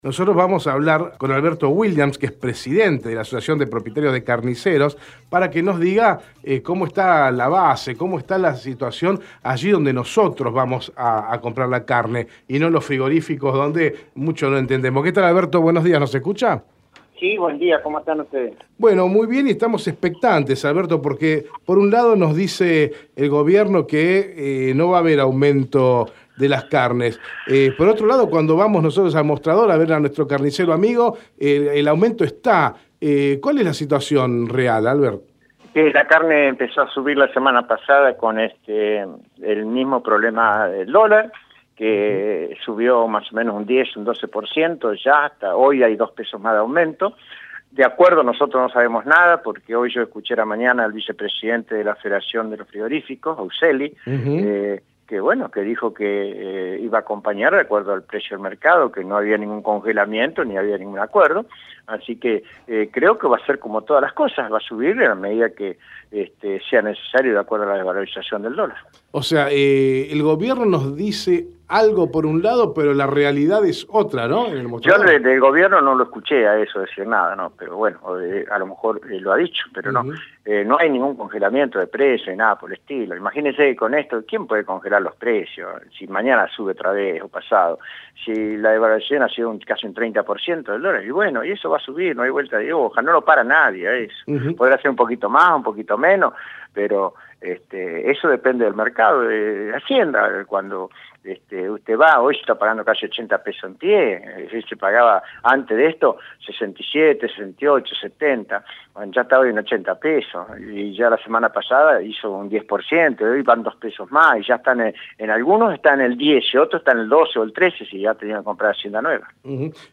Hoy en Meridiano Electoral entrevistamos a: